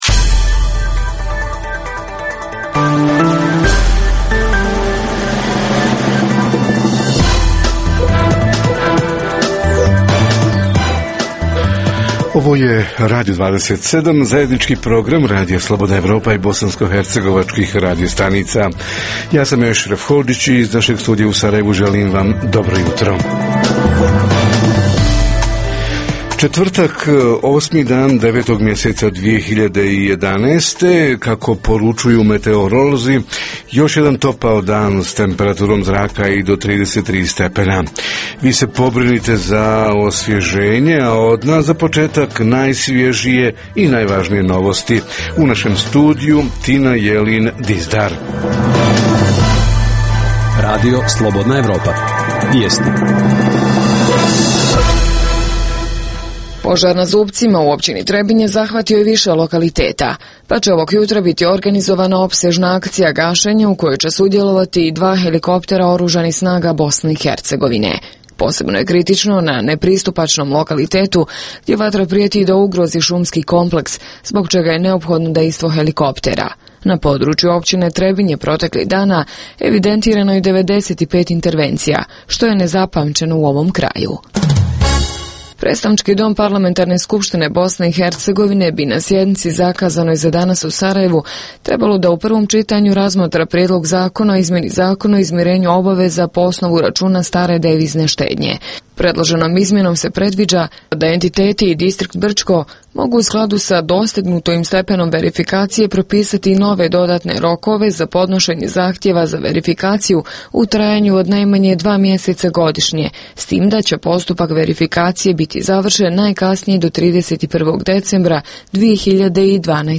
Tema jutra: uoči nove akademske godine – šta se trenutno zbiva na fakultetima, hoće li sve biti spremno za početak nastave? Reporteri iz cijele BiH javljaju o najaktuelnijim događajima u njihovim sredinama.
Redovni sadržaji jutarnjeg programa za BiH su i vijesti i muzika.